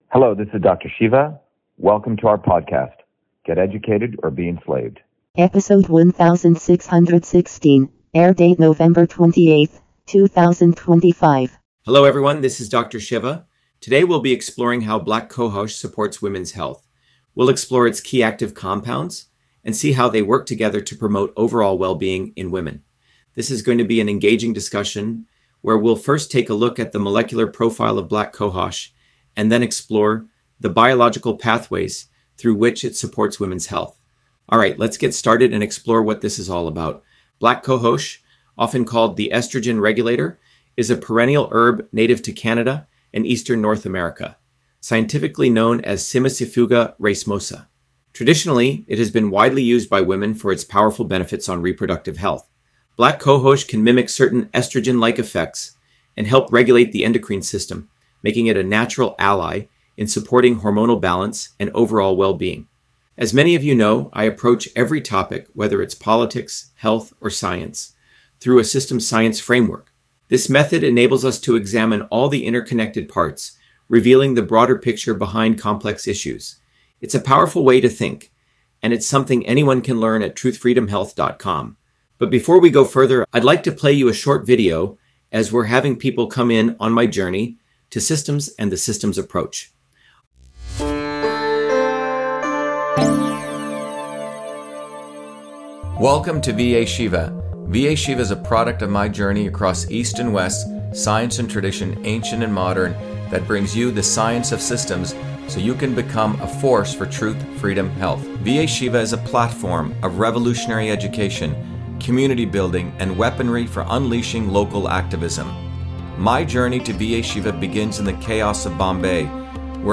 In this interview, Dr.SHIVA Ayyadurai, MIT PhD, Inventor of Email, Scientist, Engineer and Candidate for President, Talks about Black Cohosh on Women’s Health: A Whole Systems Approach